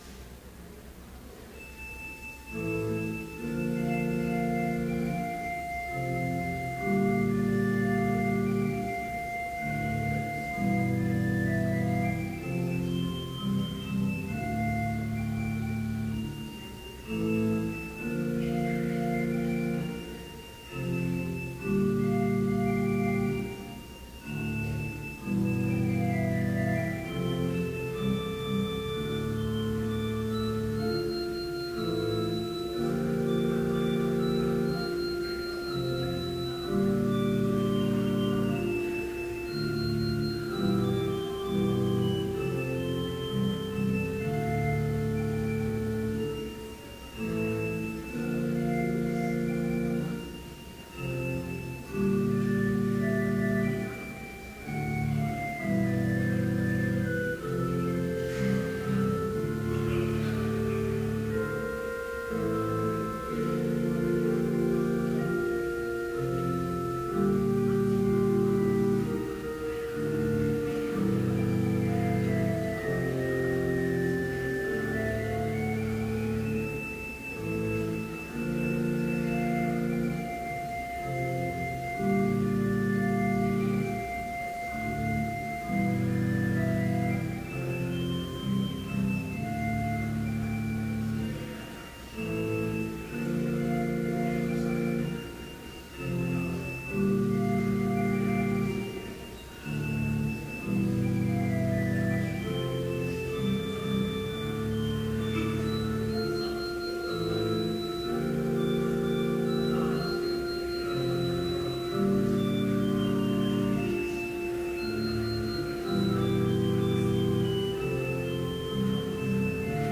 Complete service audio for Chapel - March 23, 2015